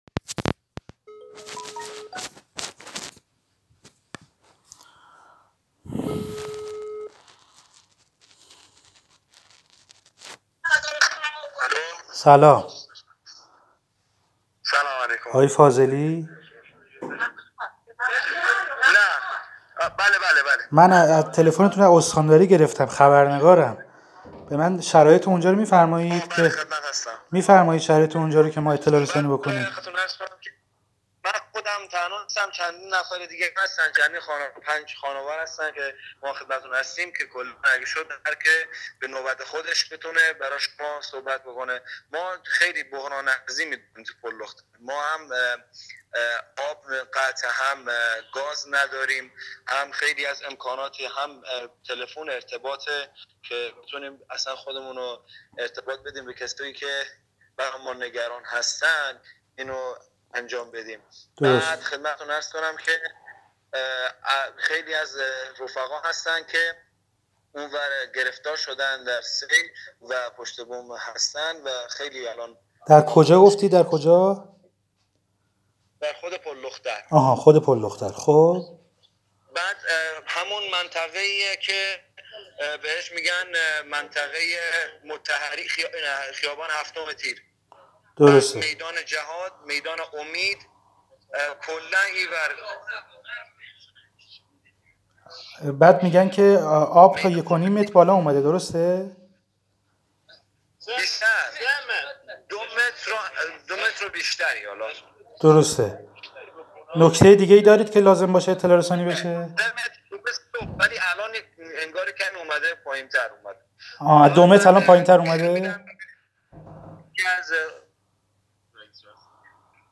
🏴 «به داد مردم پلدختر برسيد!»/ یک شهروند پلدختری: «در محاصره سيل هستيم»